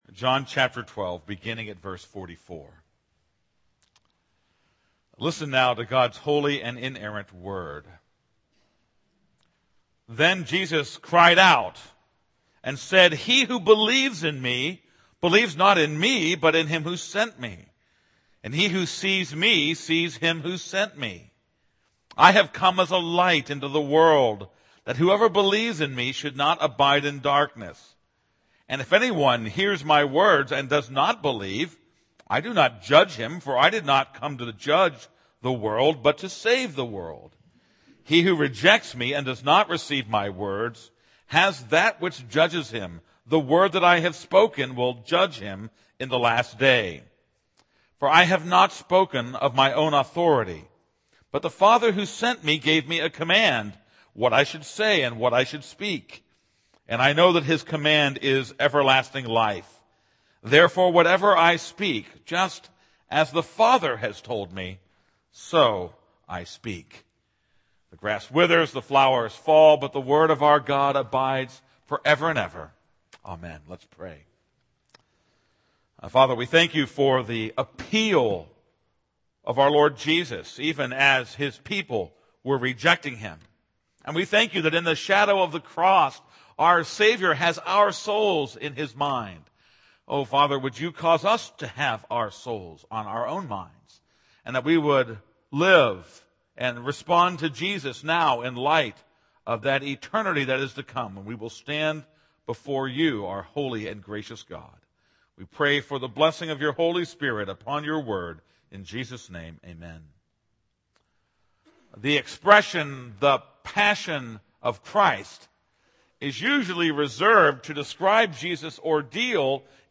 This is a sermon on John 12:44-50.